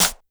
snr_58.wav